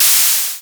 GasReleasing03.wav